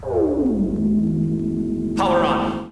Put a nifty sound on your desktop and Power On your computer with a
poweron.wav